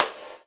Index of /90_sSampleCDs/USB Soundscan vol.10 - Drums Acoustic [AKAI] 1CD/Partition C/03-GATEKIT 3